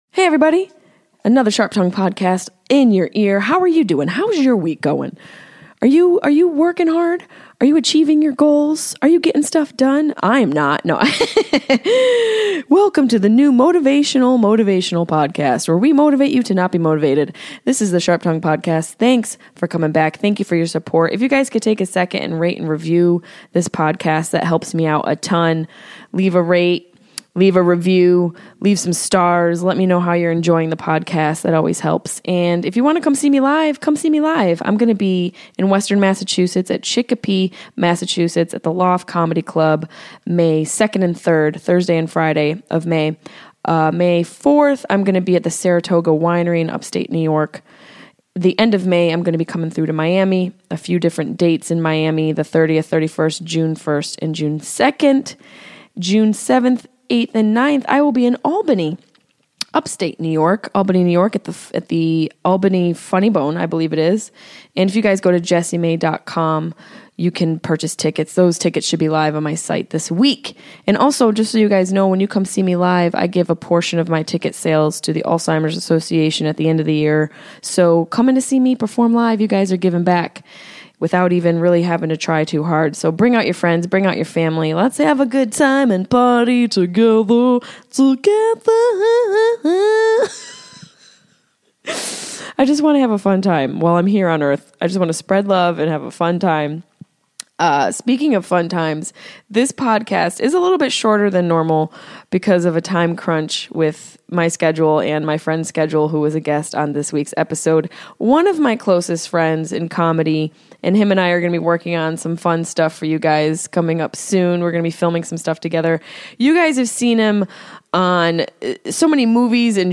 We welcome comedian, actor, and podcaster, Mr Adam Ray. We discuss that awkward moment he had with J.Lo., his love for Seattle sports, and how much we loved TGIFridays, TV lineup from the 90s, not the restaurant.